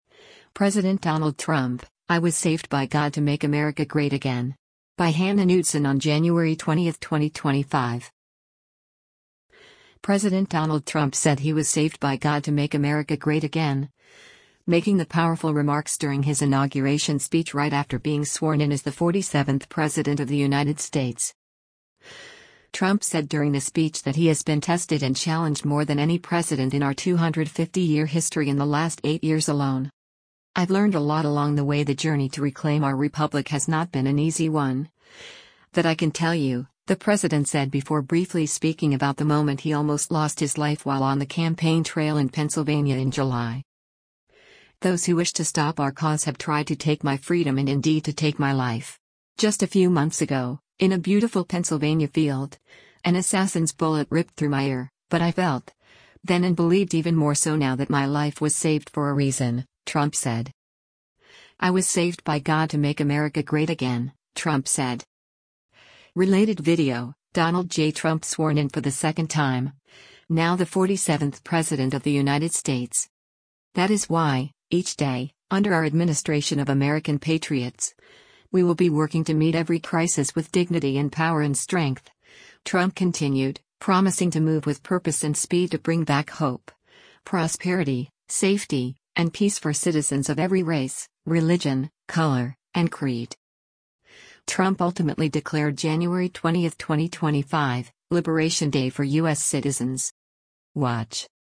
President Donald Trump said he was “saved by God to Make America Great Again,” making the powerful remarks during his inauguration speech right after being sworn in as the 47th President of the United States.